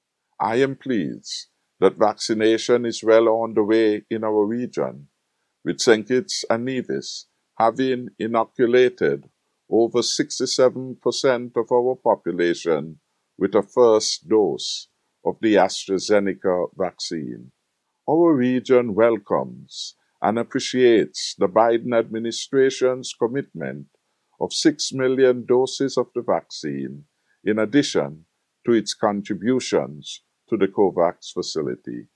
Prime Minister – Dr. Hon. Timothy Harris, represented St. Kitts and Nevis at a Virtual Public Diplomacy Engagement Forum at the gathering of the CARICOM Caucus of Ambassadors in Washington DC.
Here is an excerpt from Prime Minister Harris’ statement: